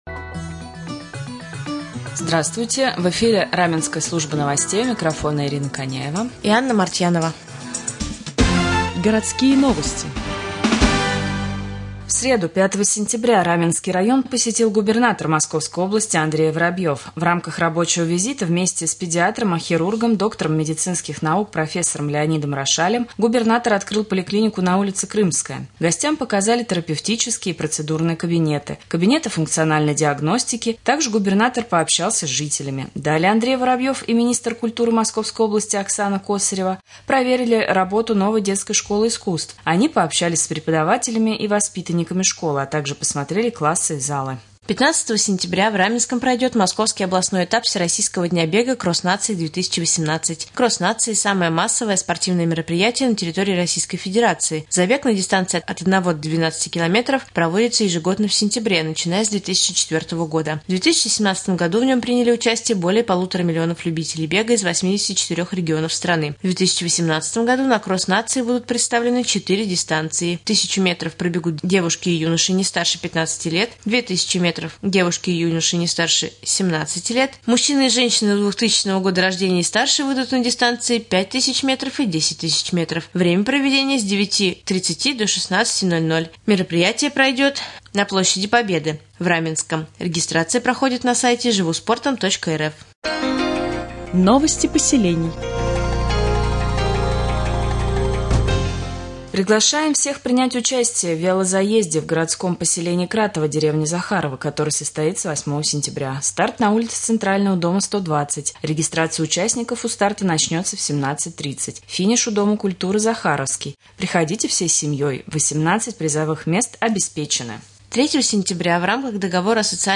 1.Новости Подмосковья и Раменского района